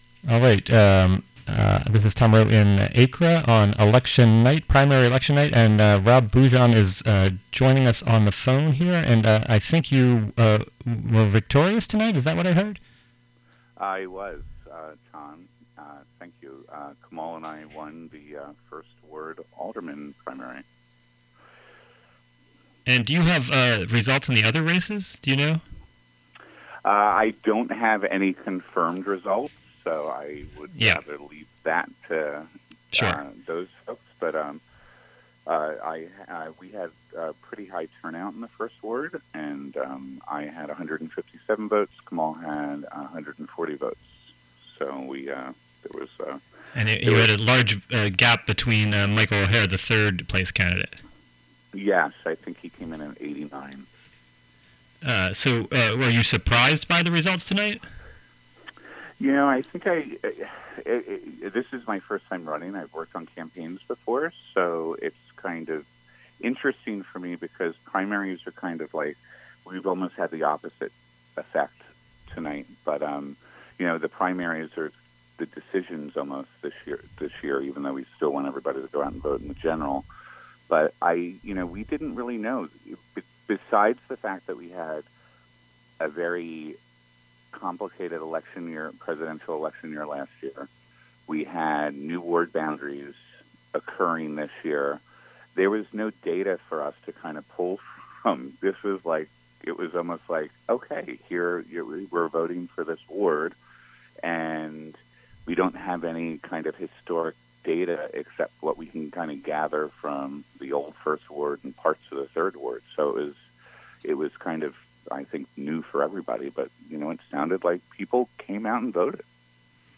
election night victory interview